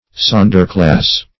Sonderclass \Son"der*class`\, n. [G. sonderklasse special